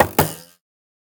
fletching_table2.ogg